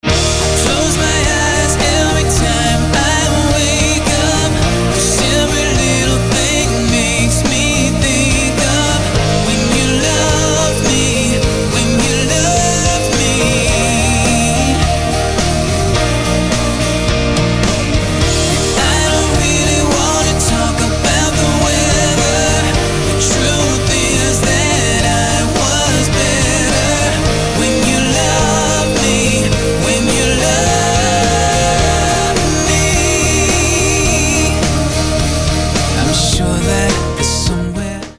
pop-rockový spebák